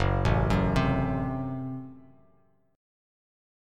F#dim chord